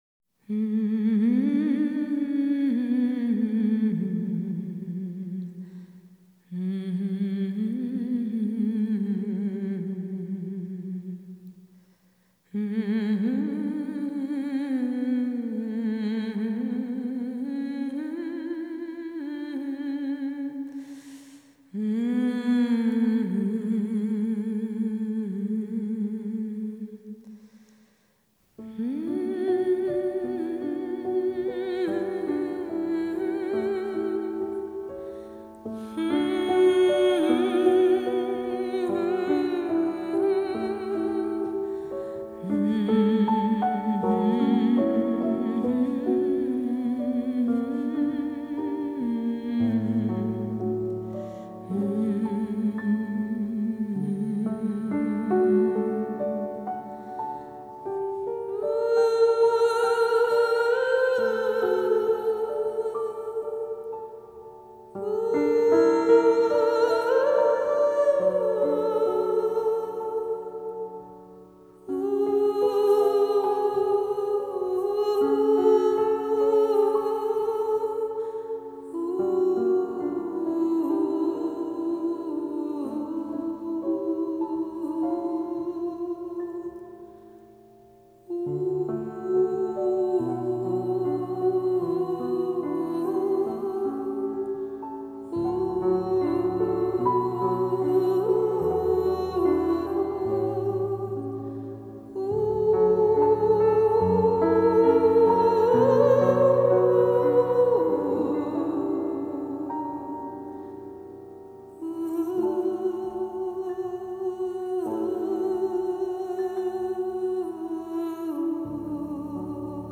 Jazz,Latin
Recorded at Stiles Recording Studio in Portland, Oregon.